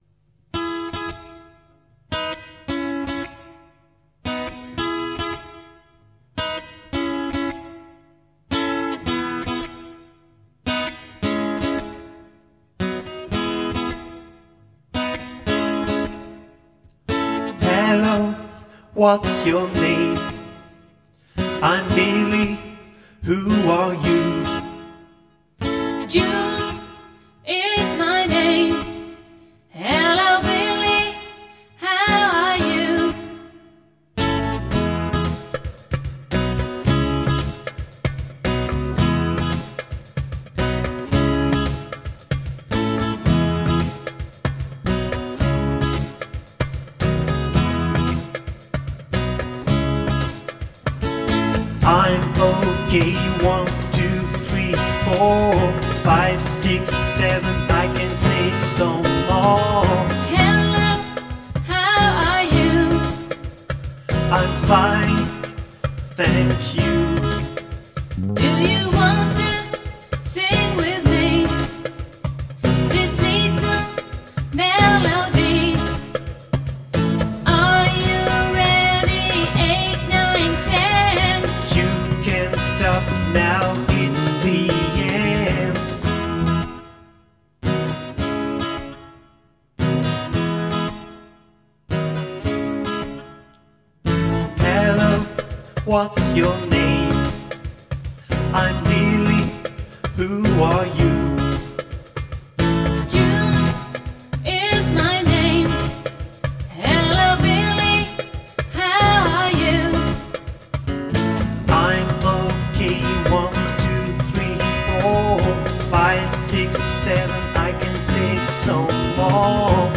Version chantée :